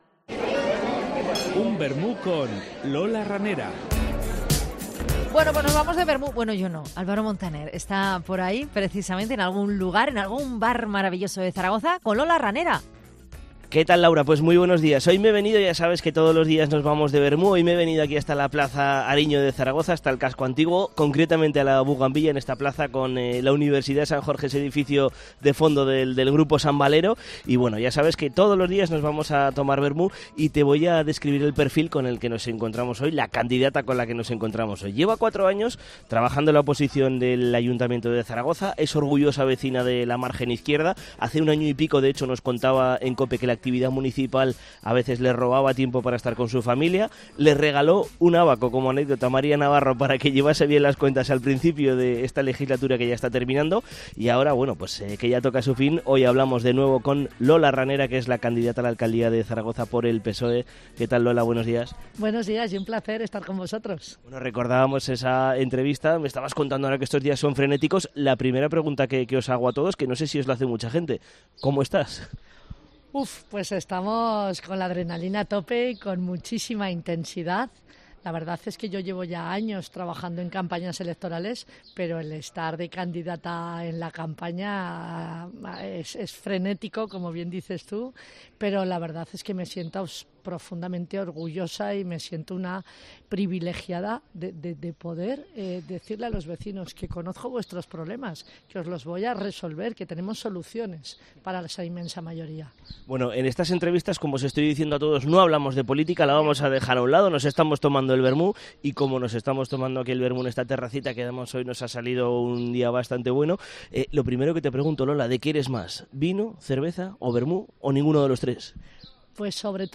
Seguimos conociendo el lado más personal de los candidatos y candidatas a la alcaldía de Zaragoza. En esta ocasión charlamos con Lola Ranera, que se presenta por el PSOE.
Nos encontramos en la Plaza Ariño, en la terraza de La Buganvilla...